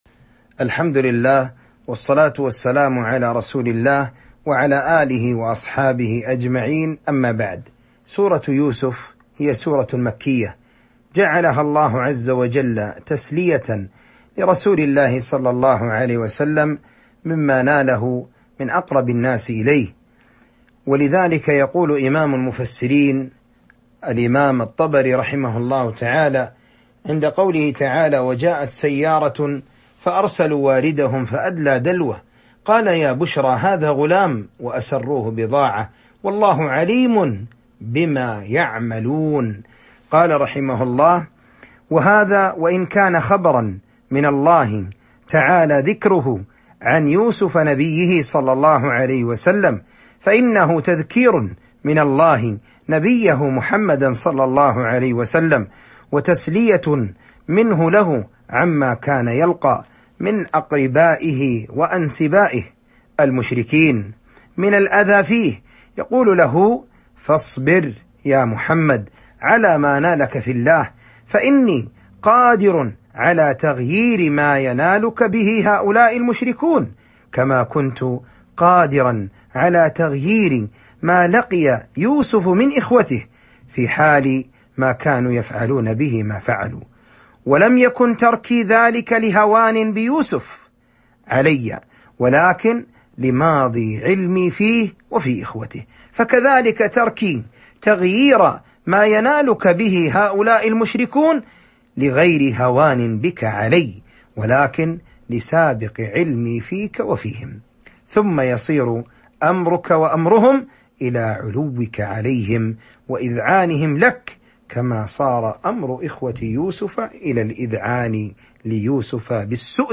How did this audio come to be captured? Format: MP3 Mono 22kHz 32Kbps (ABR)